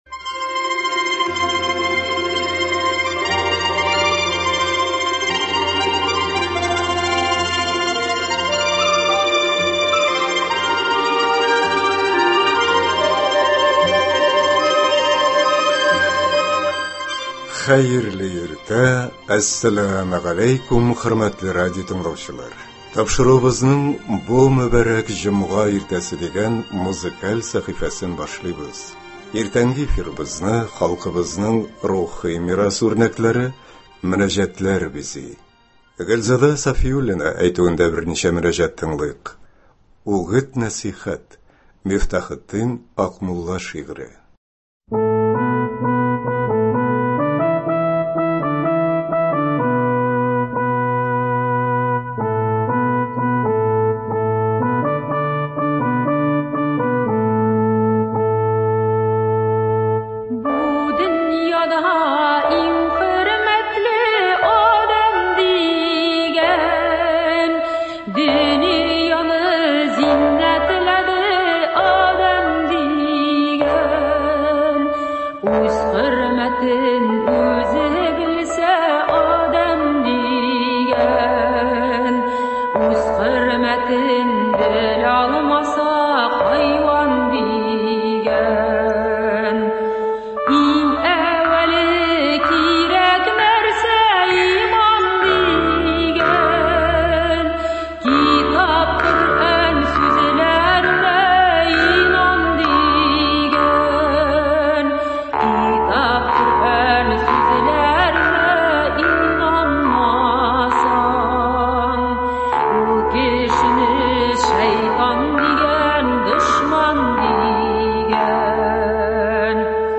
Иртәнге эфирыбызны халкыбызның рухи мирас үрнәкләре – мөнәҗәтләр бизи.